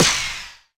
hitBaxter_Farther2.wav